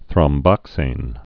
(thrŏm-bŏksān)